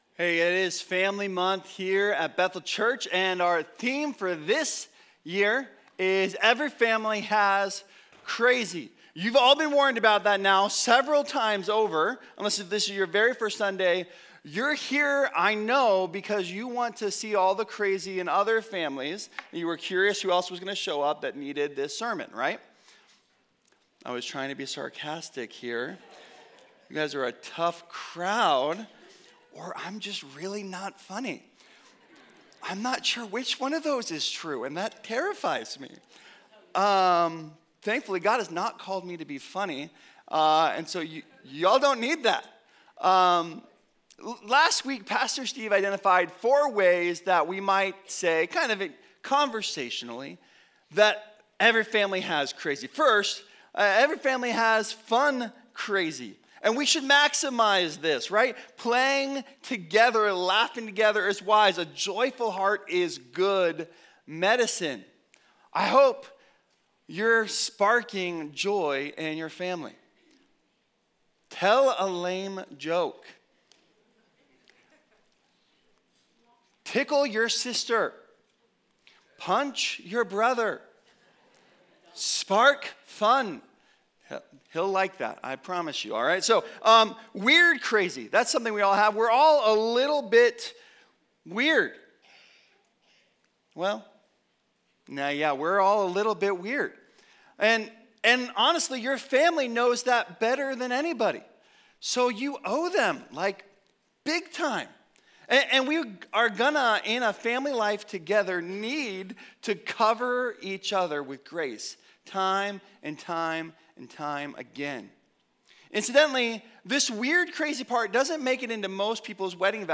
The Family is crazy... good | Every Family Has Crazy - HP Campus Sermons